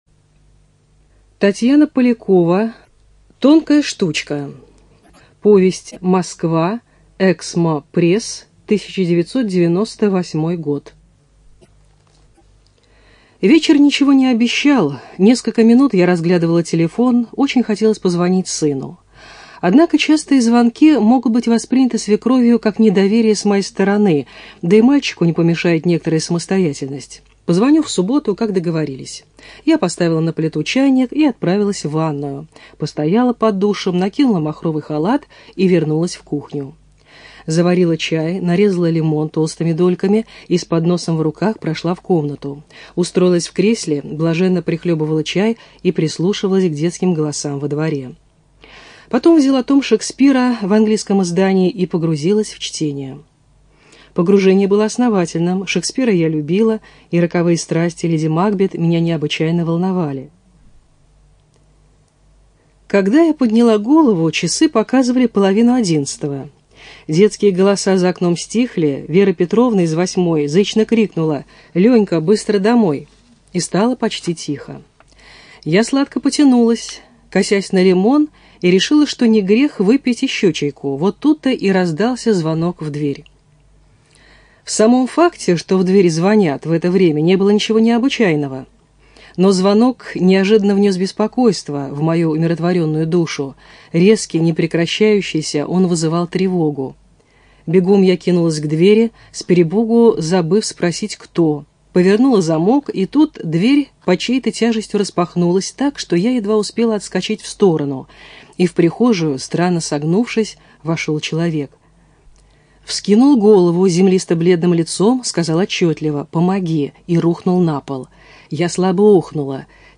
Аудиокнига Тонкая штучка - купить, скачать и слушать онлайн | КнигоПоиск